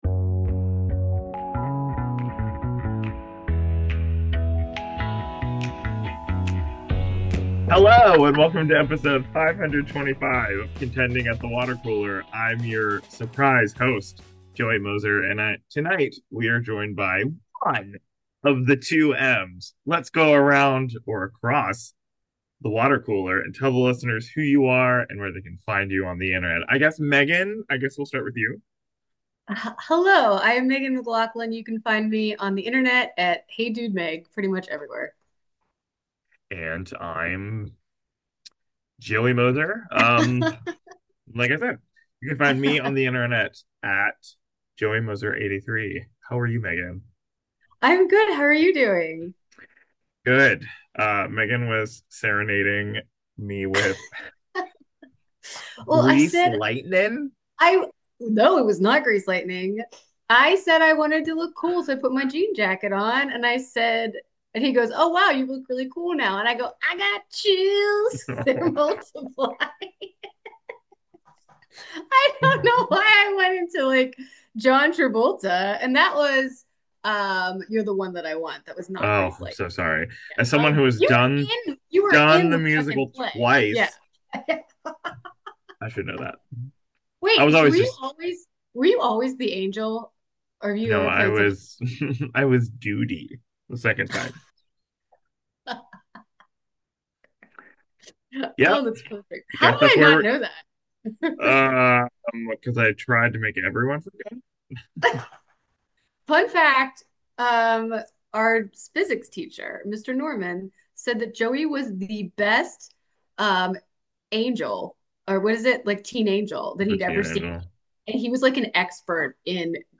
This week, the two dog owners of Contending at the Water Cooler talk about what they love about Ben Leonberg’s supernatural pup flick, Good Boy .